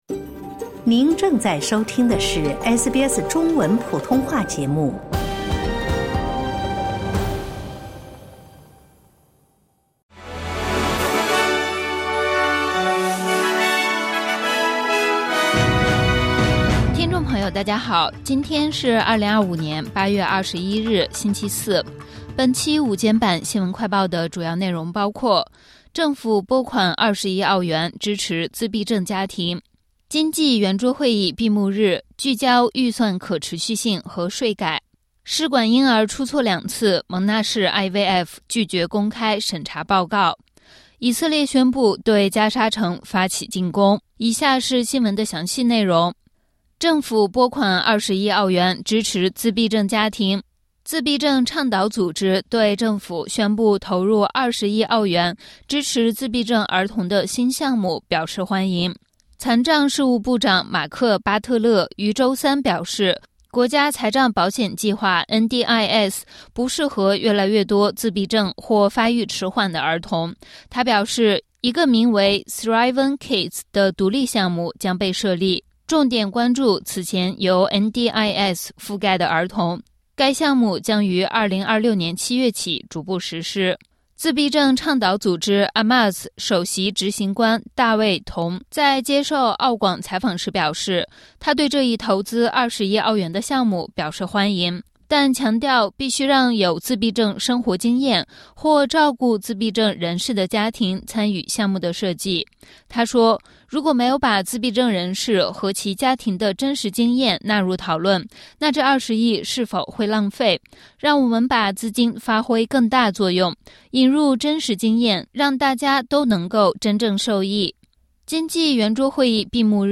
【SBS新闻快报】两类儿童将被移出NDIS 转入新项目